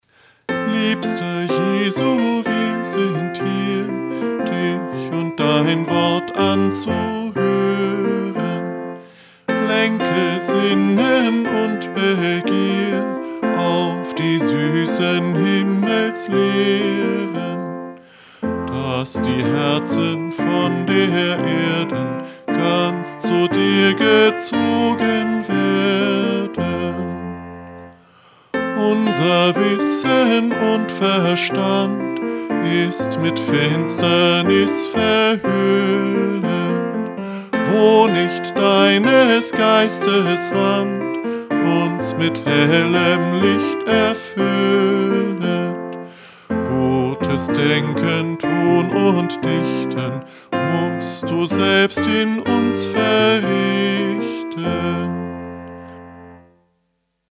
Liedvortrag